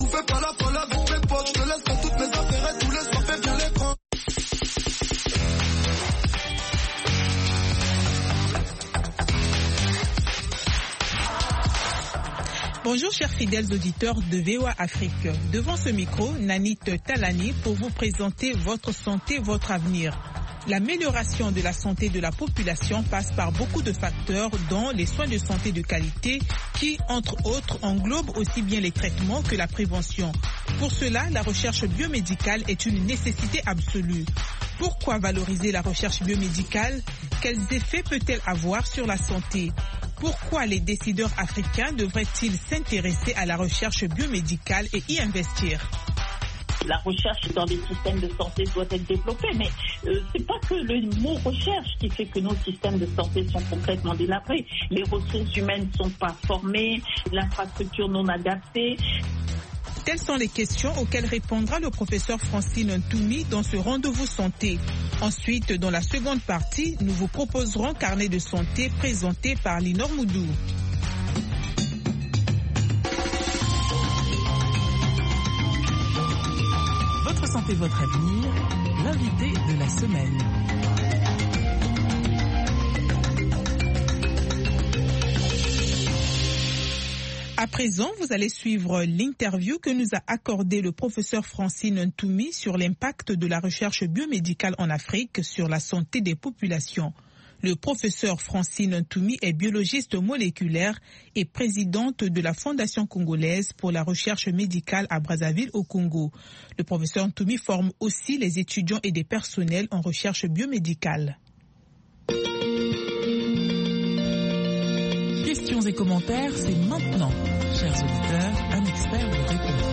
Fistules etc. Avec les reportages de nos correspondants en Afrique. VOA donne la parole aux personnes affectées, aux médecins, aux expert, aux parents de personnes atteintes ainsi qu’aux auditeurs.